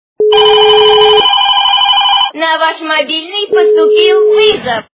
» Звуки » Смешные » Женский голос - На Ваш мобильный поступил вызов!
При прослушивании Женский голос - На Ваш мобильный поступил вызов! качество понижено и присутствуют гудки.